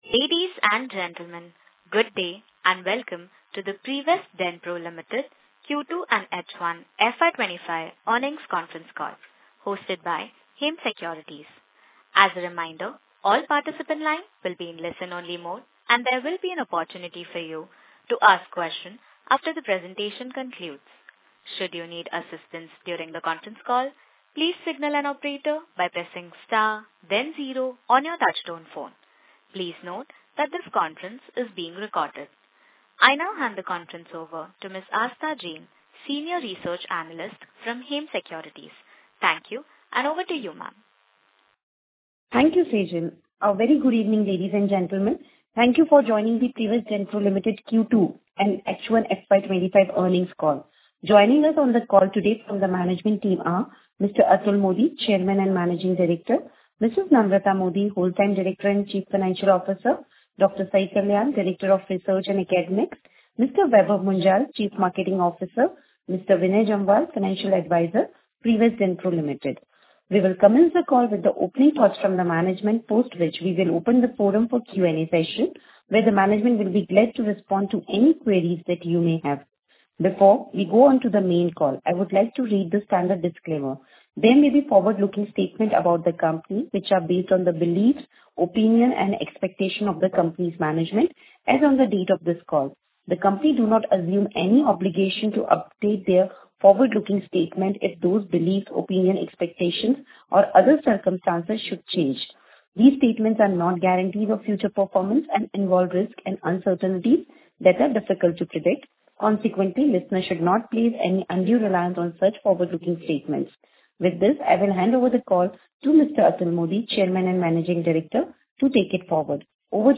Results QTR-2FY24-25 Earning Call Intimation QTR-2FY24-25 Earning Call Presentation QTR-2FY24-25 Earning Call Audio